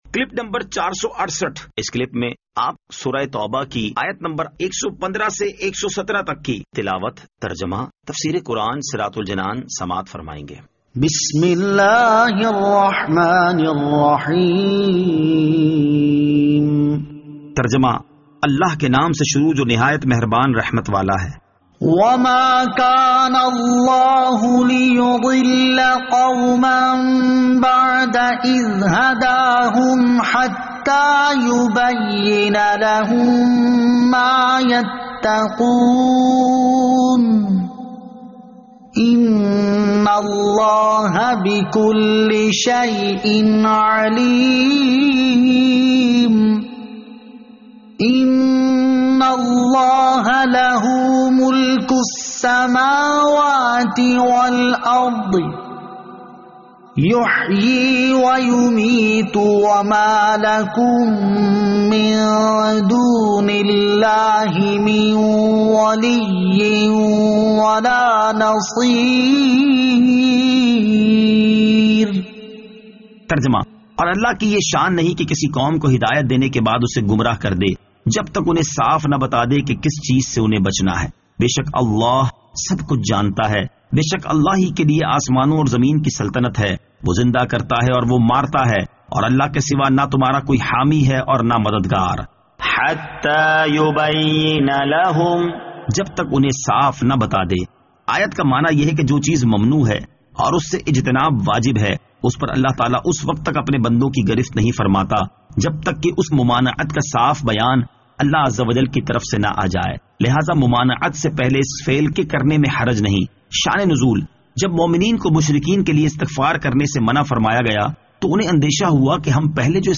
Surah At-Tawbah Ayat 115 To 117 Tilawat , Tarjama , Tafseer